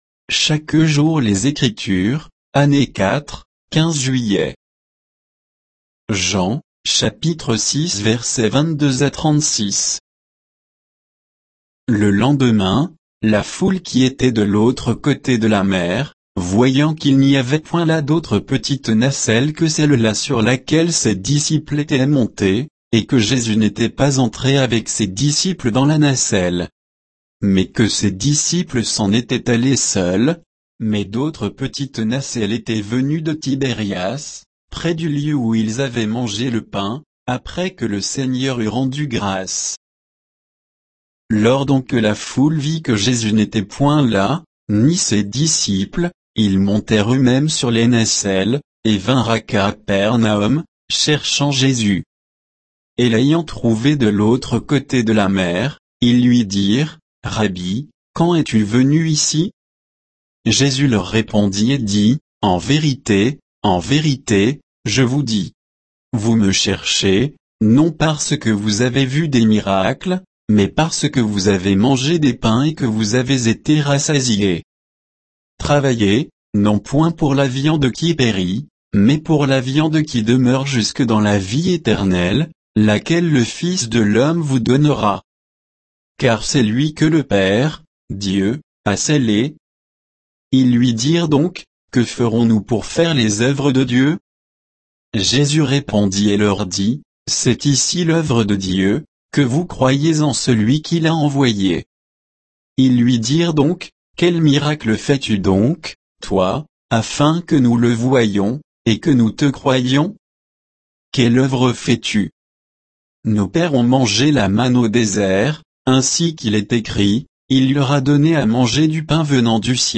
Méditation quoditienne de Chaque jour les Écritures sur Jean 6, 22 à 36